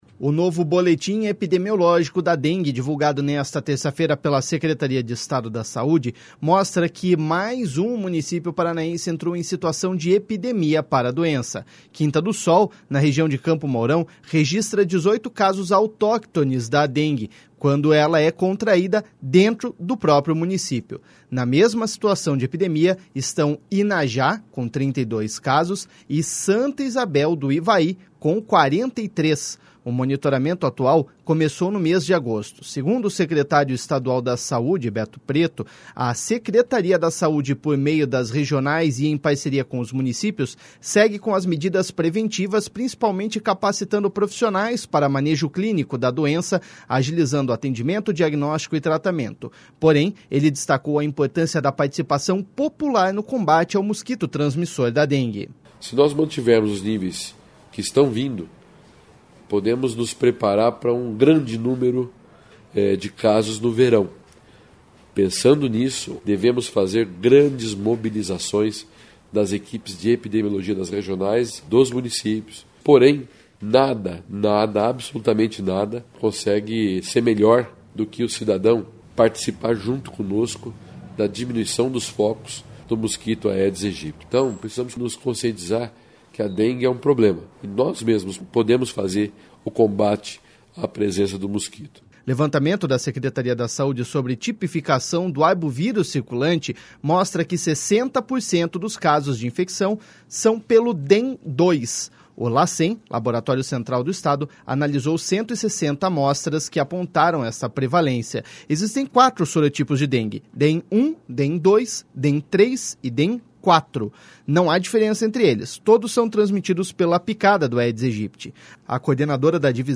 Porém, ele destacou a importância da participação popular no combate ao mosquito transmissor da dengue.// SONORA BETO PRETO.//